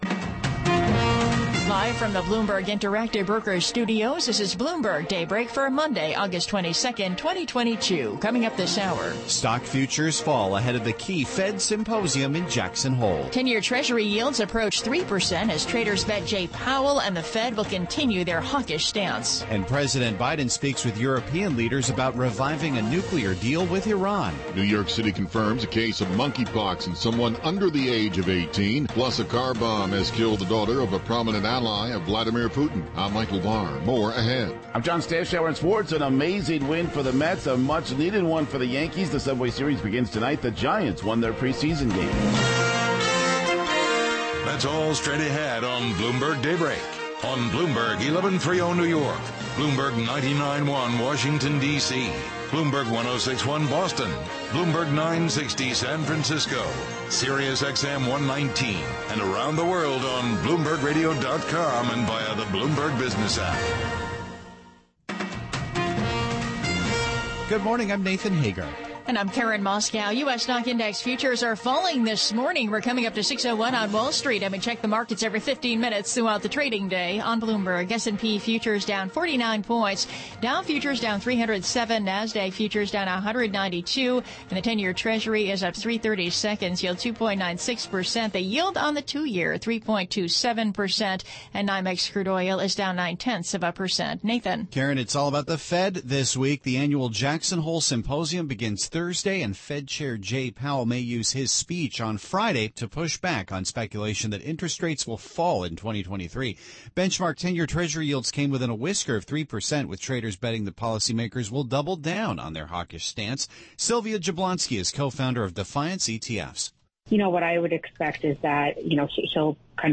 Bloomberg Daybreak: August 22, 2022 - Hour 2 (Radio)